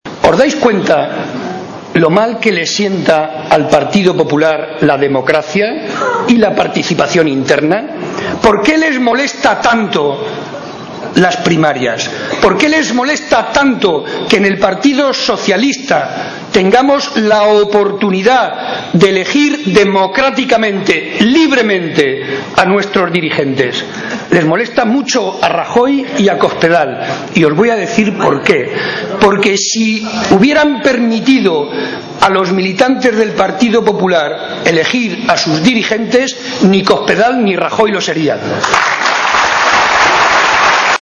Momento del acto celebrado en Campo de Criptana.